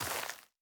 DirtRoad_Mono_01.wav